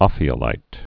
(ŏfē-ə-līt, ōfē-)